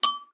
A stereo recording of a fire alarm cut to loop.
Fire Alarm
bell ding fire-alarm loop ring stereo xy